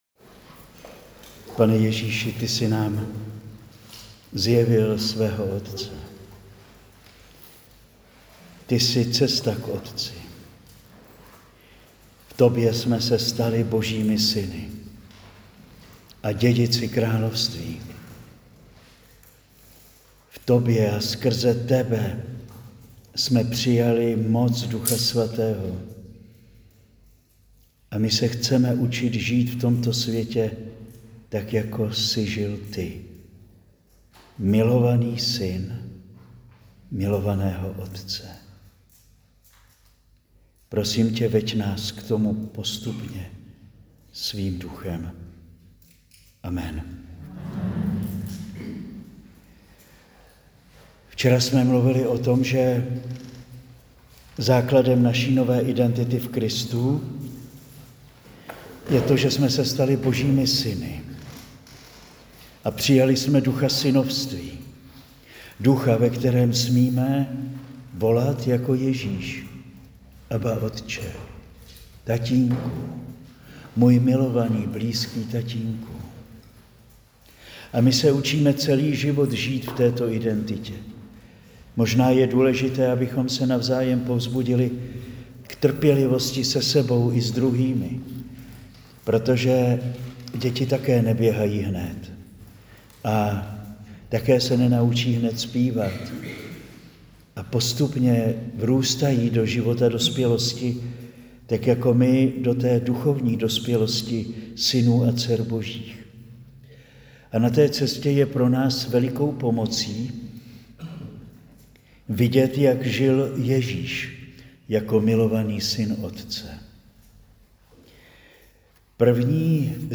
Přednáška zazněla na kurzu učednictví v květnu 2025